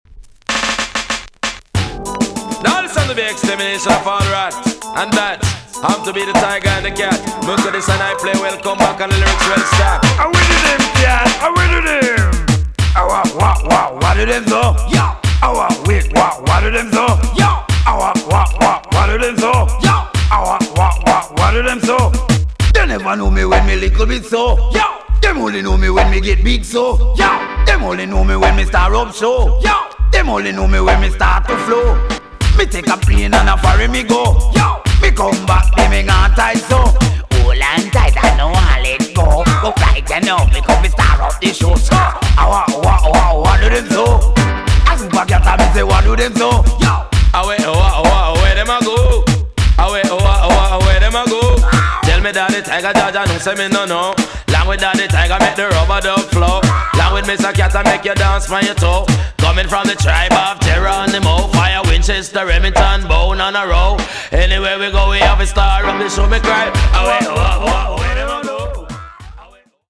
EX- 音はキレイです。
1991 , RARE , WICKED COMBINATION TUNE!!
GHETTO RED HOT RIDDIM!!